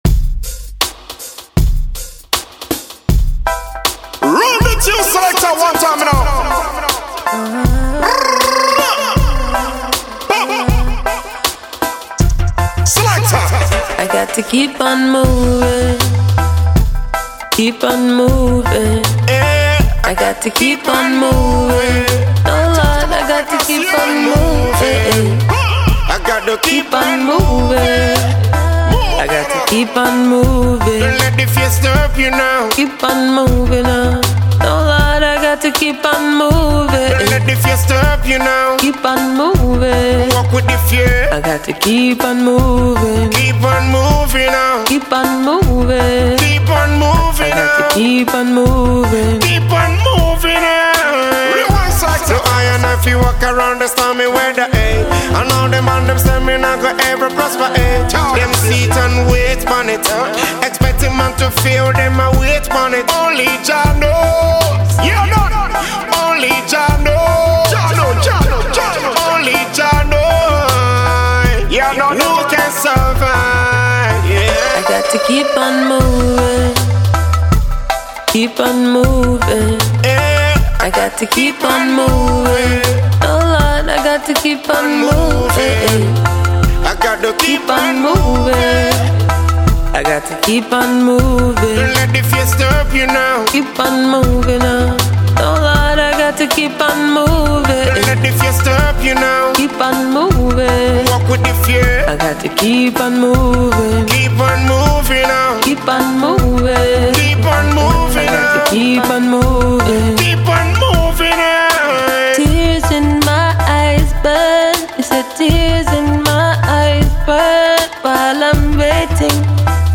Afro-Dancehall star
mid-tempo tune
is smooth, rhythmic and harmonious